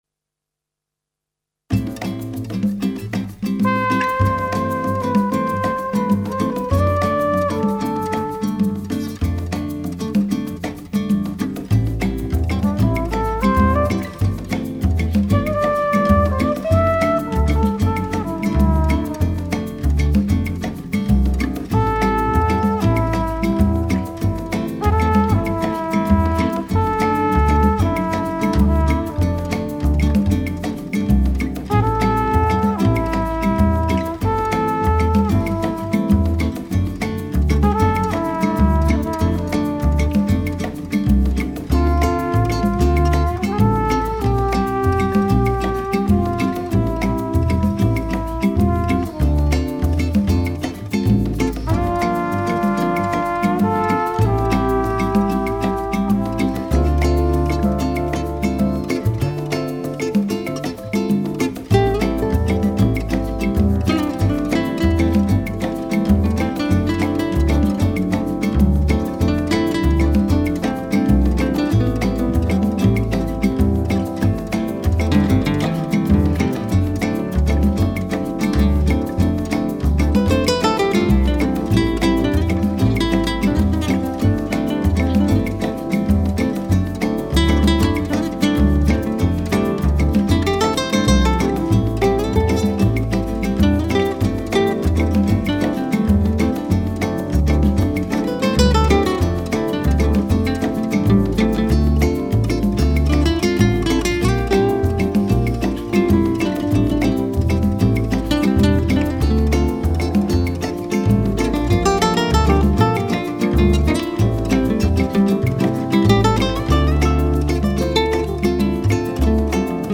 for Piano, Bass, Horn, Guitar, Drums & Percussion